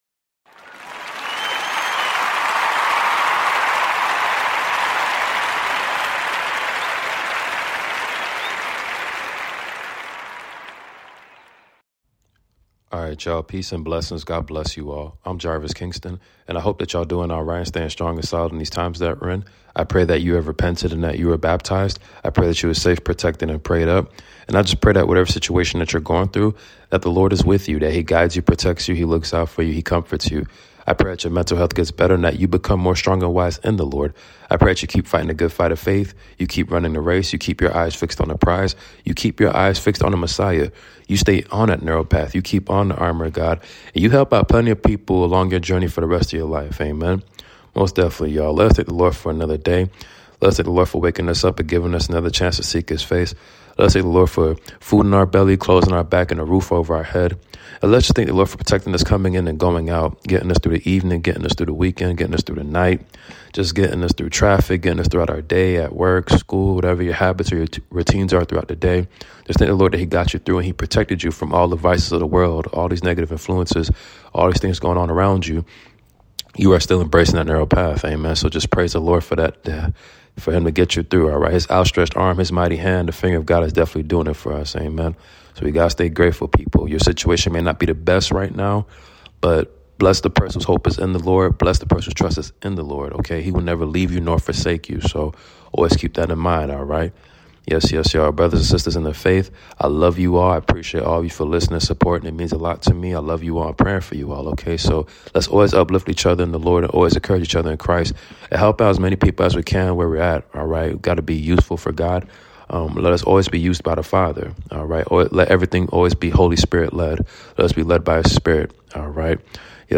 Commentary reading based on Moses Father